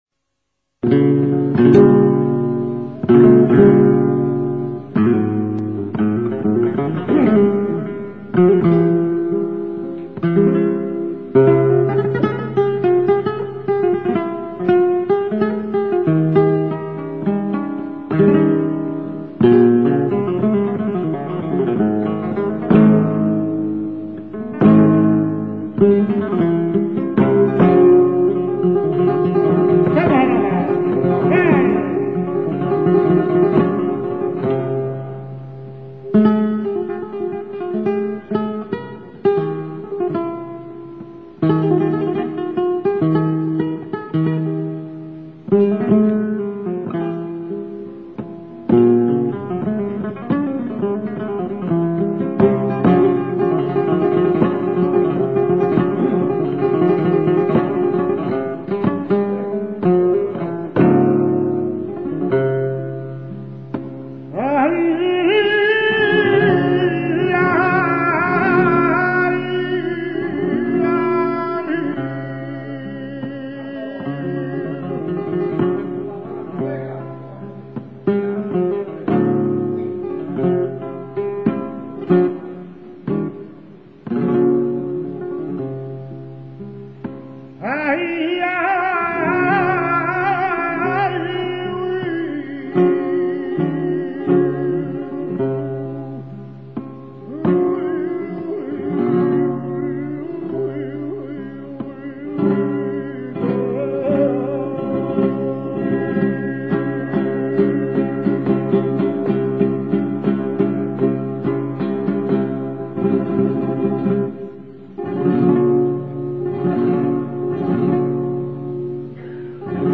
Es un cante dram�tico, fuerte, sombr�o y desolador, que est� considerado en su condici�n de b�sico como uno de los estilos m�s exponentes de la esencia jonda del cante flamenco.
Se toca a la guitarra por en medio y es uno de los cantes m�s dif�ciles de interpretar por su cantidad de matices y el conocimiento que es preciso tener de �l para encajar los tercios en la medida de su m�sica.
seguirilla.mp3